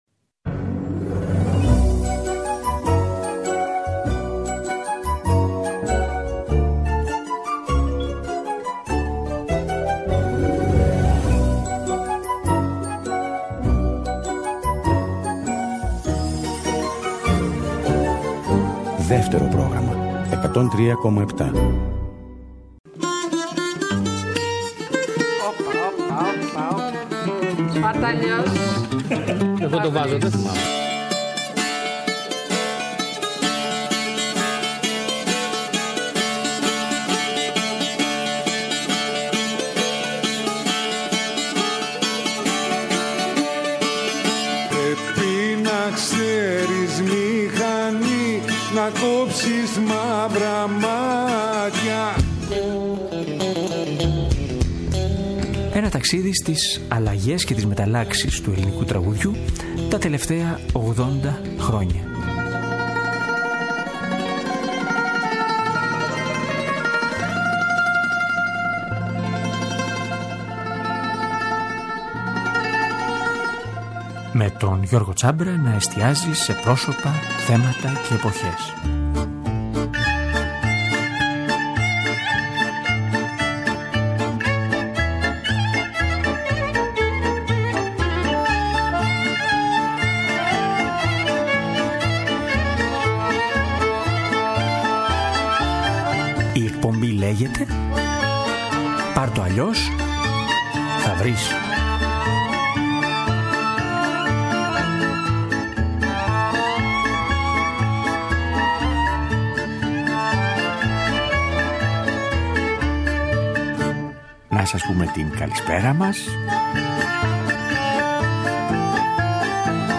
Θέματα, «σημειώσεις» και κυρίως τραγούδια από όσα πέρασαν από την εκπομπή σ’ αυτό το διάστημα…